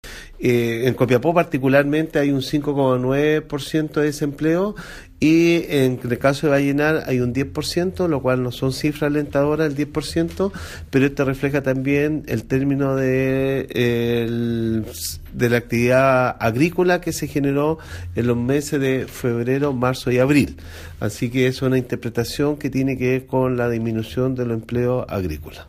“En Copiapó tenemos un 5,9% de desempleo, mientras que en el caso de Vallenar nos preocupa el 10% ya que no es una cifra alentadora, sin obstante la cifra refleja el término de la temporada agrícola lo que repercute en el análisis”, comentó Mario Silva.
Seremi-Economía-2.mp3